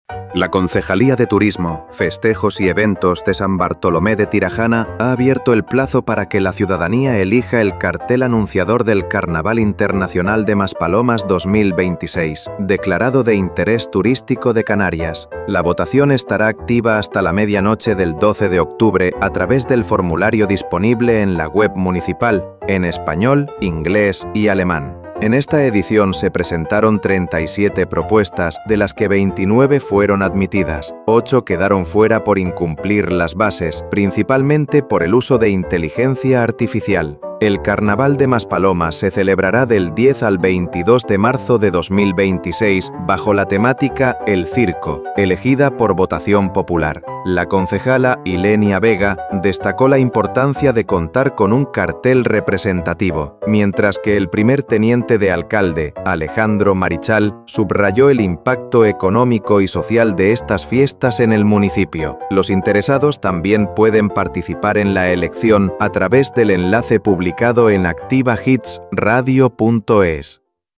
AUDIO-CARTEL-CIRCO-CON-MUSICA.mp3